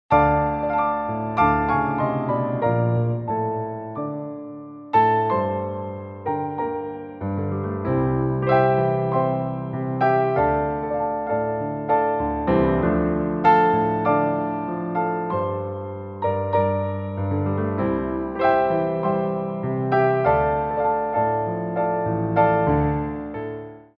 Développés
12/8 (8x8)